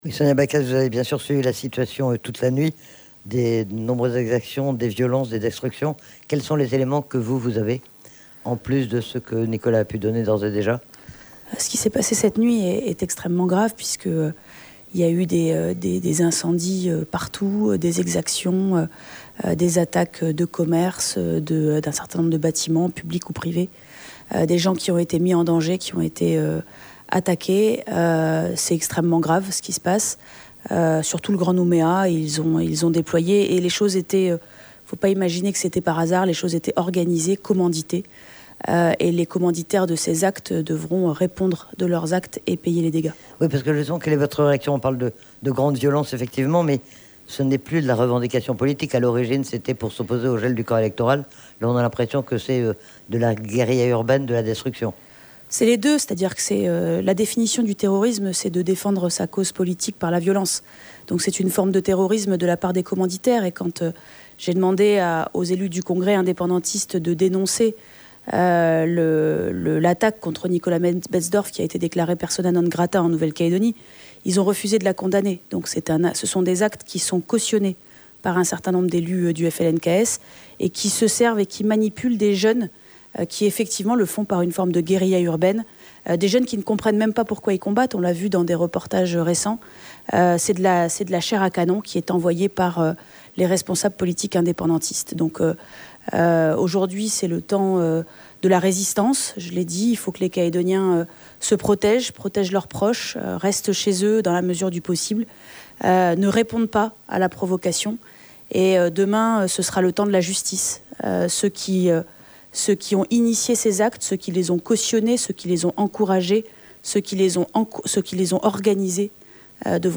Menu La fréquence aux couleurs de la France En direct Accueil Podcasts SONIA BACKES SUR RRB SONIA BACKES SUR RRB 13 mai 2024 à 19:05 Écouter Télécharger La présidente de la province Sud est intervenue en direct dans le journal de 6h30.